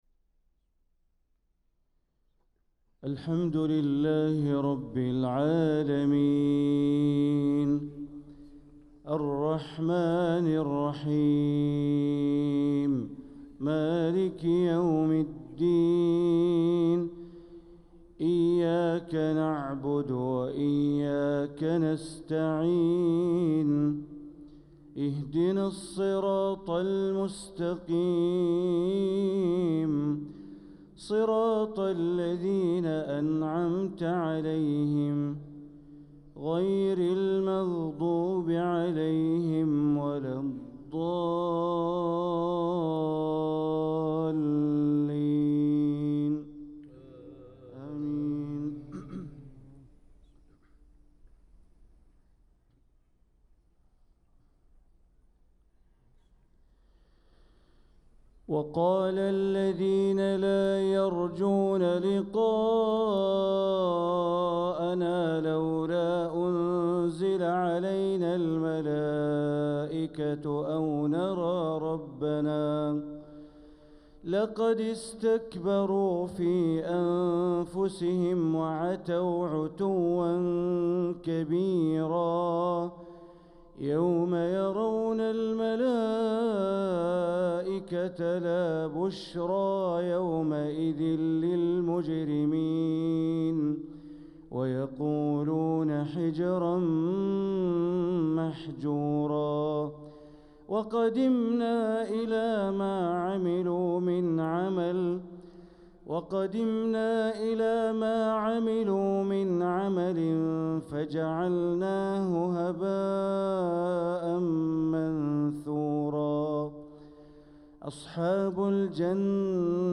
صلاة الفجر للقارئ بندر بليلة 25 محرم 1446 هـ